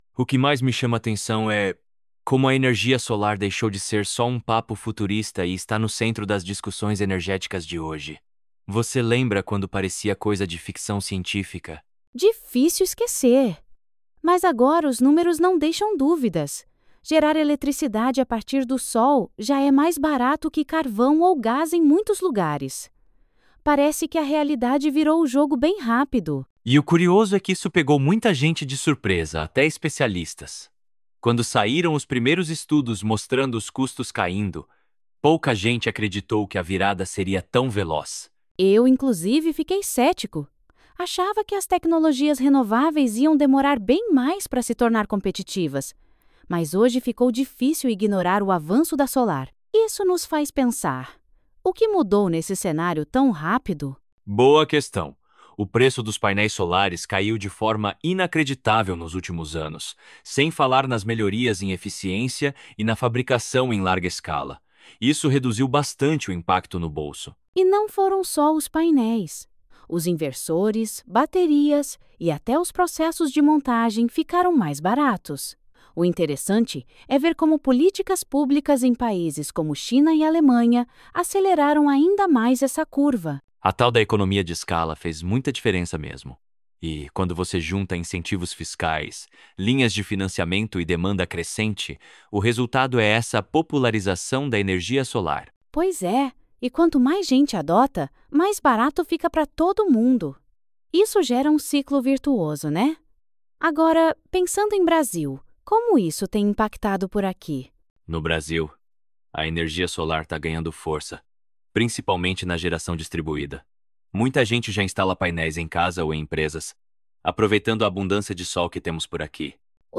PODCAST IAs Nossos Comentaristas Pixel e Byte